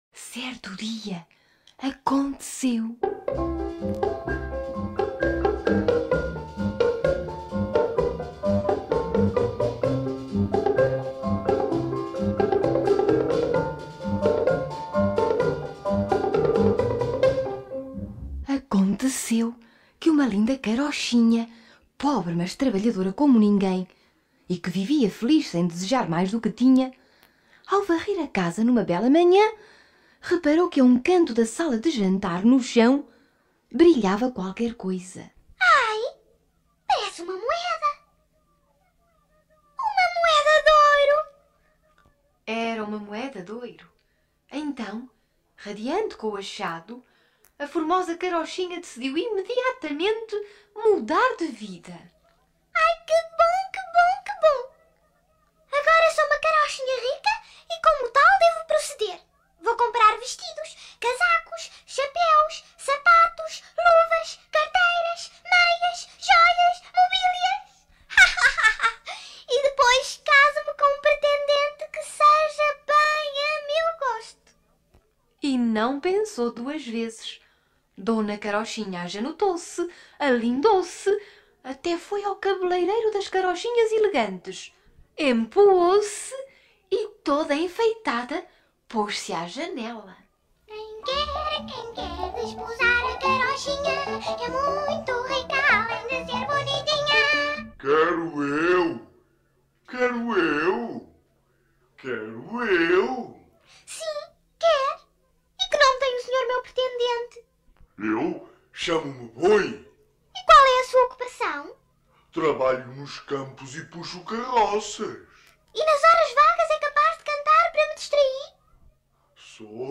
Local da Gravação: Estúdio A da Rua do Quelhas, e no Estúdio de São Marçal
João Ratão: João Perry